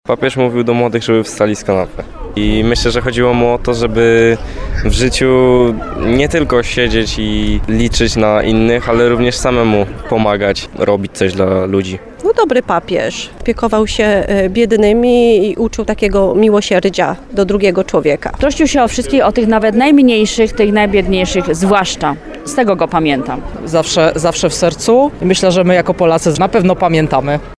– Papież mówił do młodych, żeby wstali z kanapy. Myślę, że chodziło mu o to, żeby w życiu nie tylko siedzieć i liczyć na innych, ale również samemu pomagać i robić coś dla ludzi – mówi młody mężczyzna.